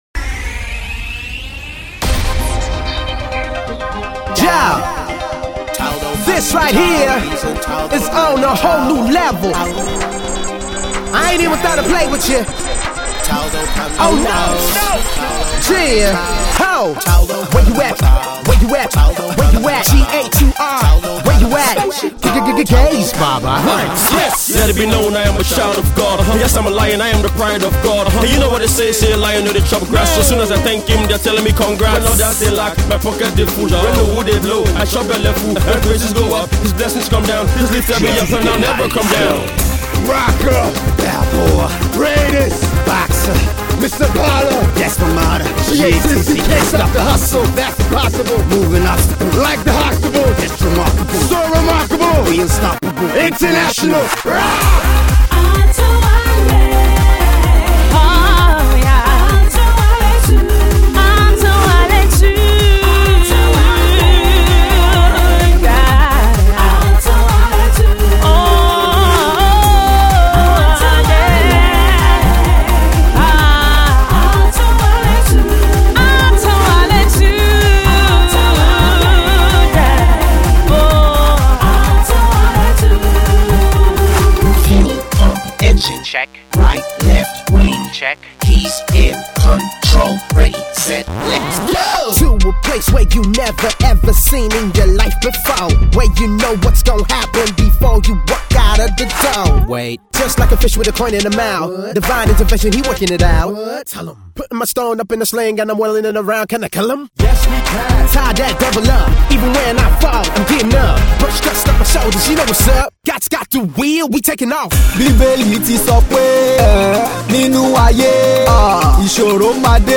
If you loved Gospel diva